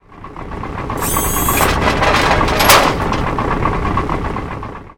droneout.ogg